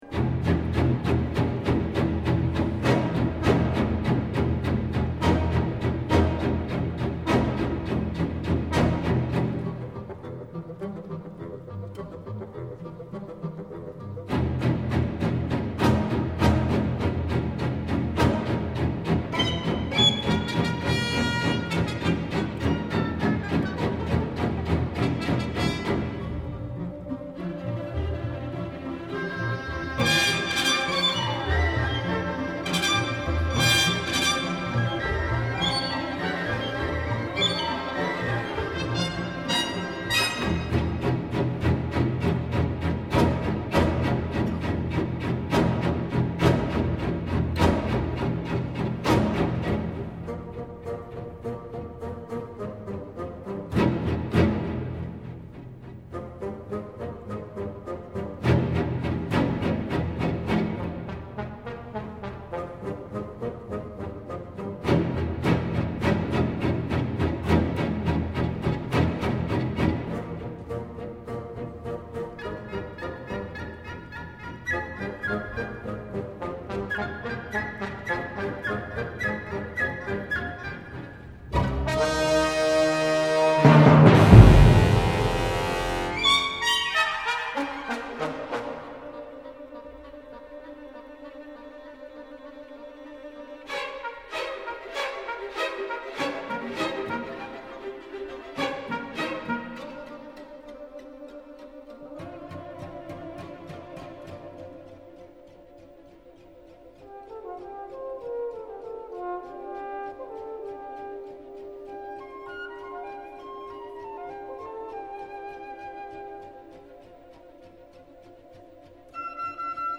Orchestre Philharmonique de Berlin, Herbert Von Karajan – 1977 *****
Le chef, qui propose en 1977 sa seconde version enregistrée, creuse les timbres, sculpte les sonorités et privilégie une approche lyrique qui n’exclut pas la sauvagerie dans la première partie, l’orchestre est d’une qualité époustouflante et la prise de son est à la hauteur, tant en matière de timbres que d’image sonore et de dynamique.